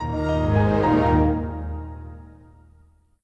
Windows NT XP Shutdown.wav